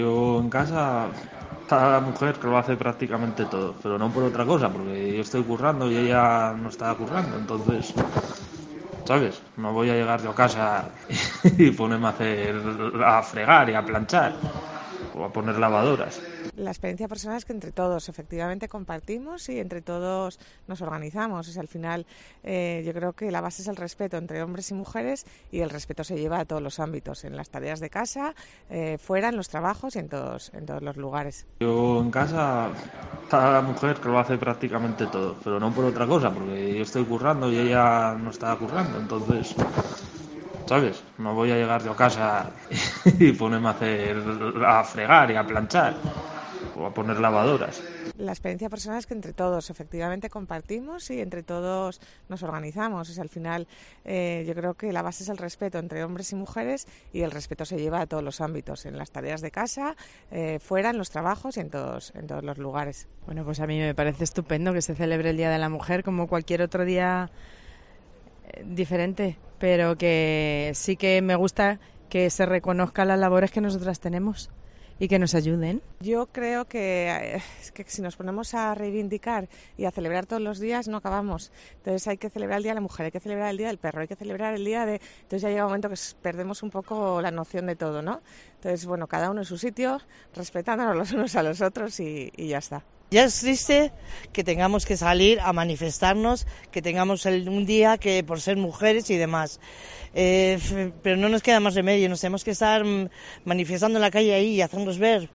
Voces opiniones 8M
O al menos, eso indica la muestra que hemos recogido por las calles de Santander.